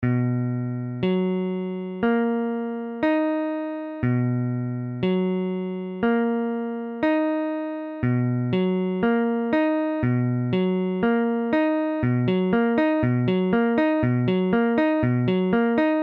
Tablature B7M.abcB7M : accord de Si septième majeure
Mesure : 4/4
Tempo : 1/4=60
A la guitare, on réalise souvent les accords de quatre notes en plaçant la tierce à l'octave.
Si septième majeure barré II (fa dièse case 2 si case 2 fa dièse case 4 doigt 3 la dièse case 3 doigt 2 ré dièse case 4 doigt 4 fa dièse case 2)